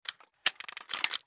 На этой странице собраны звуки шестерёнок разного типа: от мягкого перекатывания до резкого металлического скрежета.
Вставили инструмент, чтобы запустить шестеренку в наручных часах